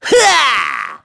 Neraxis-Vox_Casting2.wav